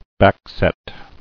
[back·set]